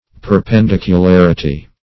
Perpendicularity \Per`pen*dic`u*lar"i*ty\, n. [Cf. F.